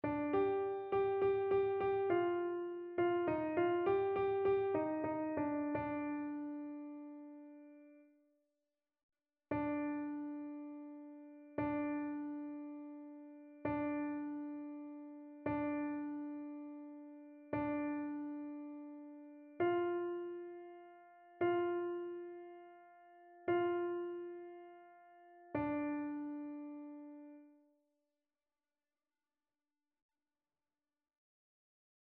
Paroles : AELF - Musique : JFD
annee-a-temps-ordinaire-4e-dimanche-psaume-145-alto.mp3